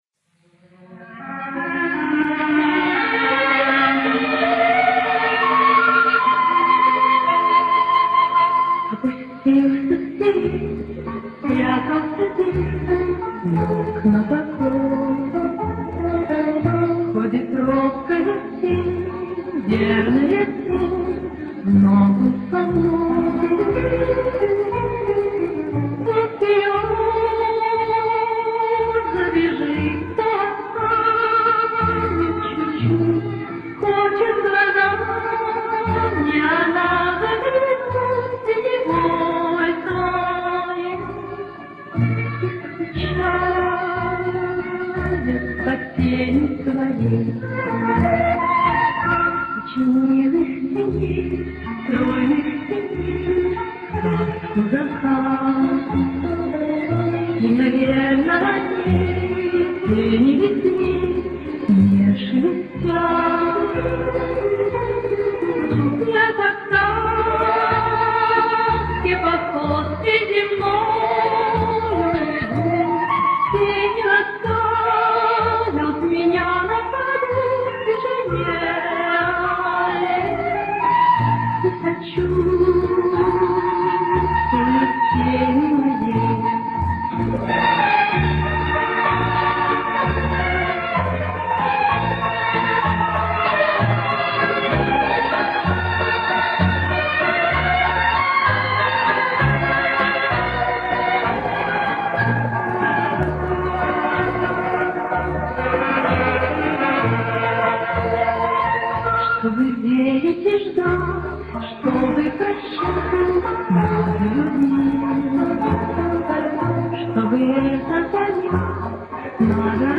Качество записи, к сожалению, не самого хорошего качества.